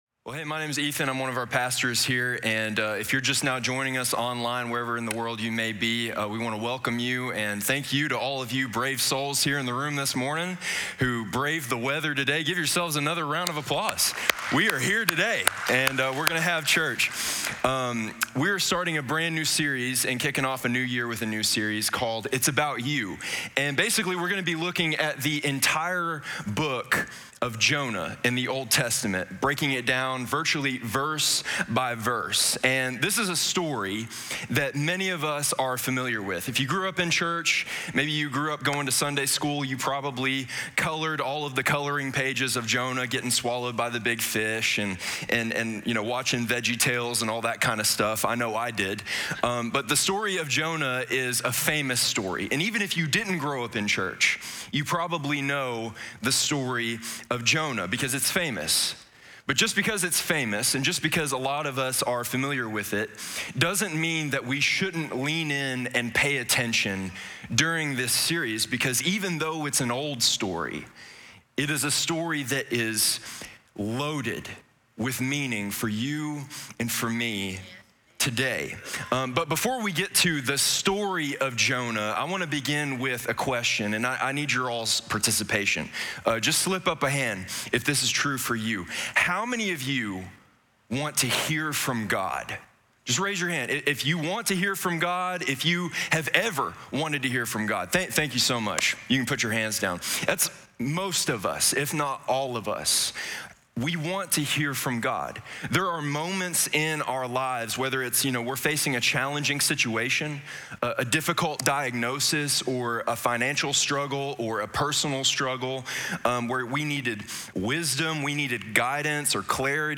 25_1_5_ItsAboutYou_Wk1_SermonOnly.mp3